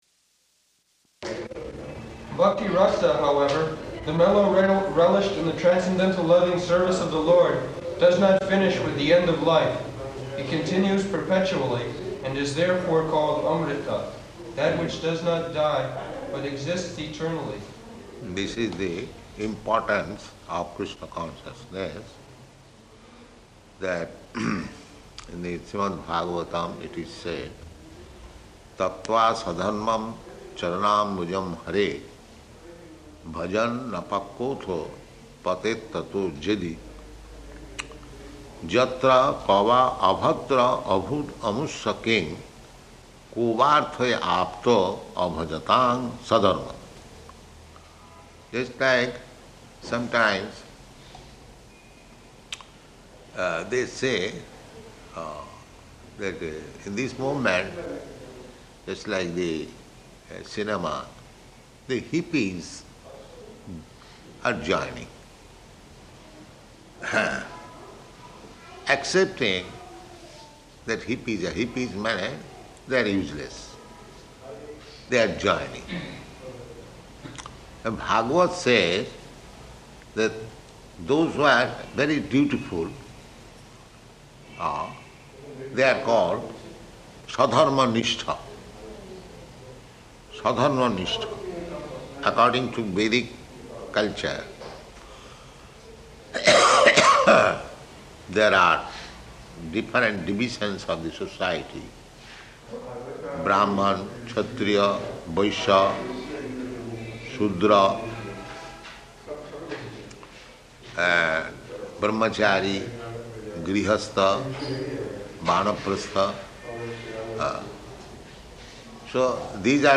Location: Bombay